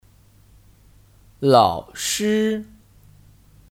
老师 (Lǎoshī 老师)